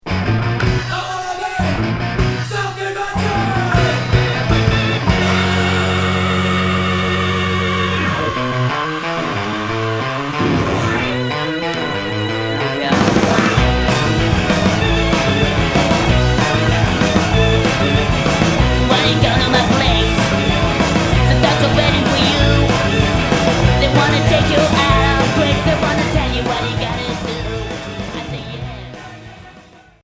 Garage Unique 45t